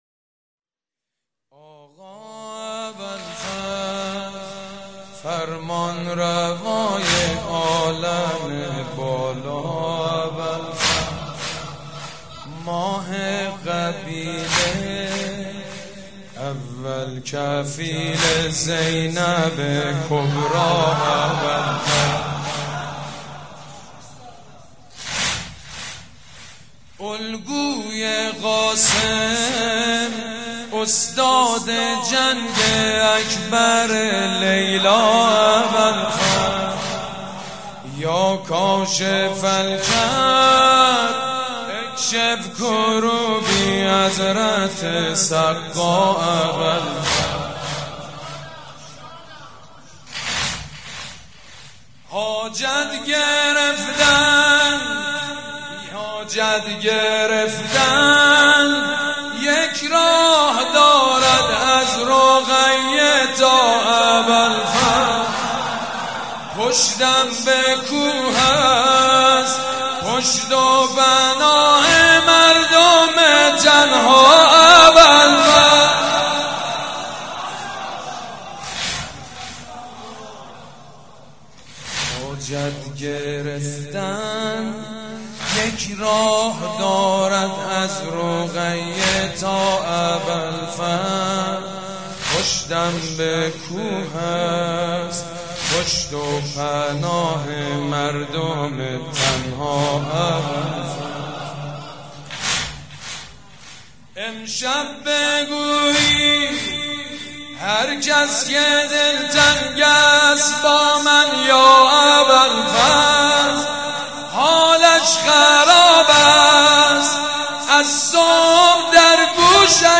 آقا ابالفضل فرمانروای عالم بالا سيد مجيد بنی فاطمه شب نهم تاسوعا محرم 96/07/7
آقا ابالفضل فرمانروای عالم بالا _ واحد سيد مجيد بنی فاطمه شب نهم تاسوعا محرم 96/07/7